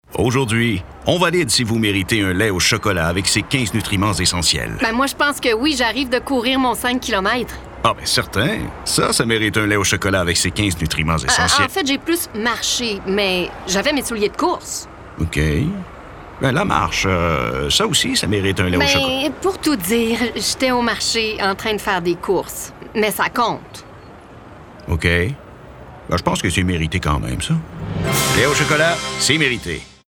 Français (Canada)
Profonde, Fiable, Mature
Commercial